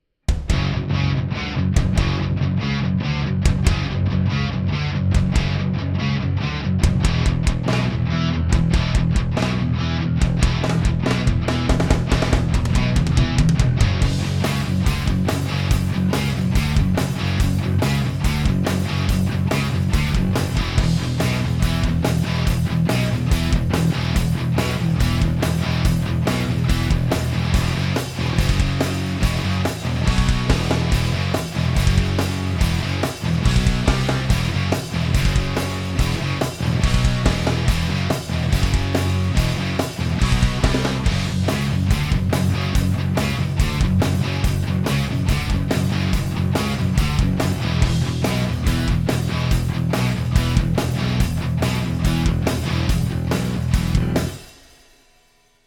Just straight in, green, gain rolled way back.
It's piled up a bit, 5 takes per side. Drums are a whatever I threw together in like ten minutes just to hear something.